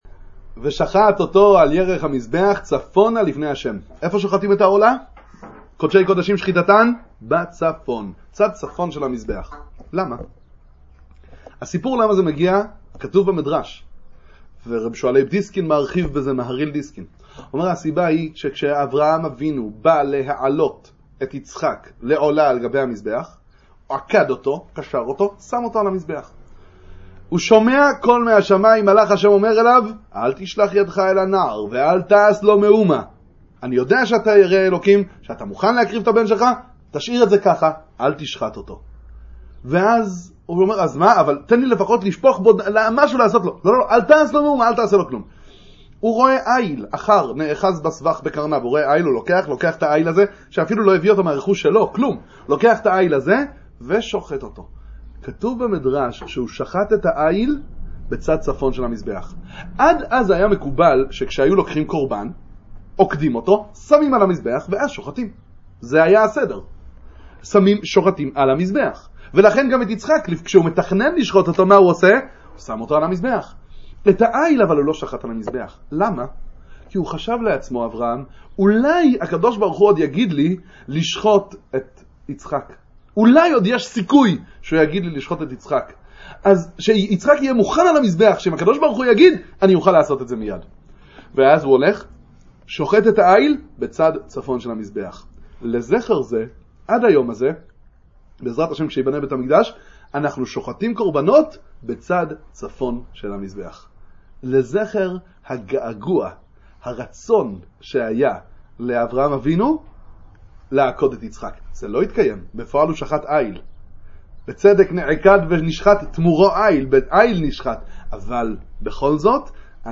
זכרון הגעגוע, דבר תורה קצר לפרשת ויקרא ושבת זכור